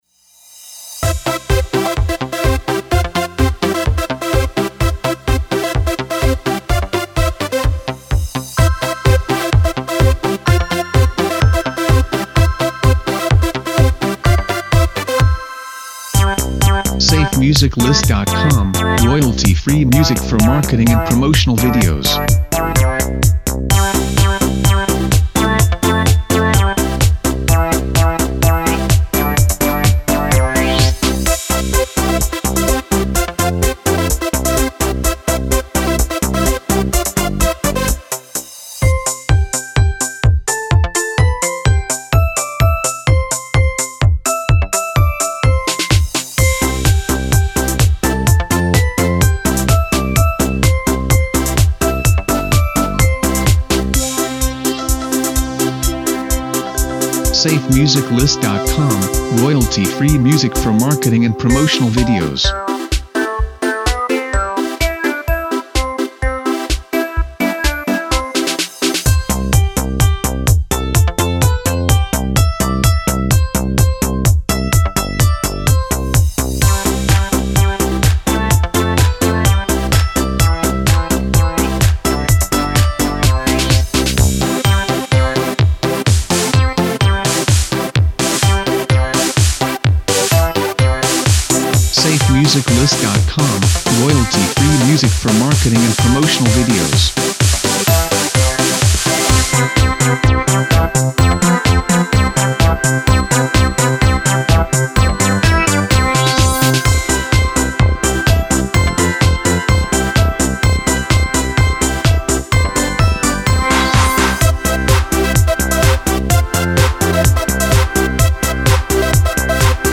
BPM: 127